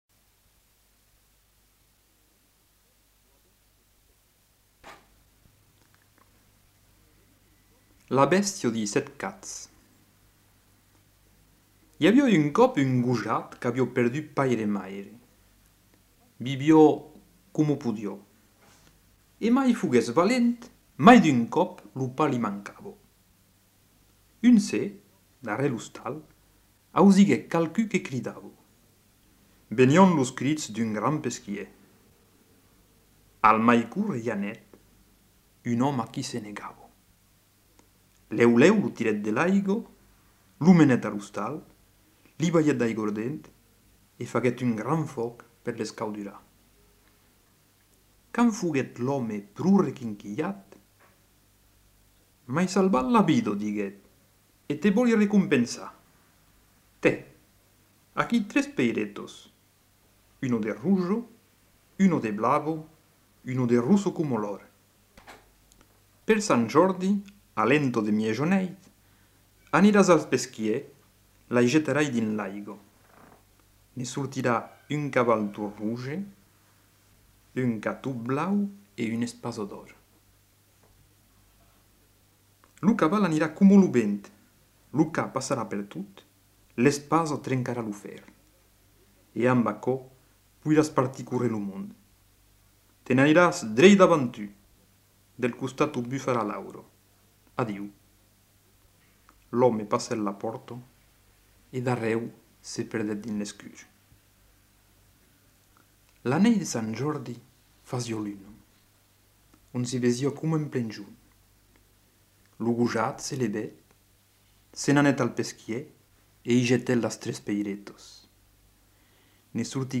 Genre : conte-légende-récit
Type de voix : voix d'homme Production du son : parlé
archives sonores en ligne Contenu dans [enquêtes sonores] Trois contes occitans